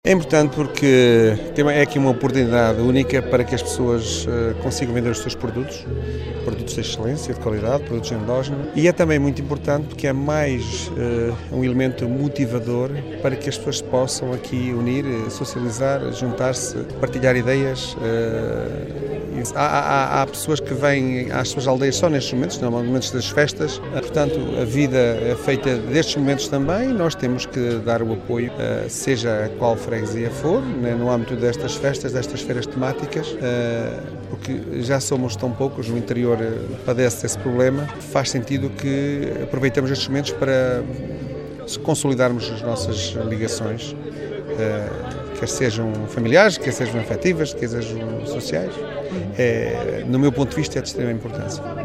No ato de inauguração, o executivo de câmara municipal de Macedo de Cavaleiros esteve presente com Susana Viana, Vereadora da Ação Social e Rui Vilarinho, Vice-Presidente, que enalteceu a importância destas iniciativas: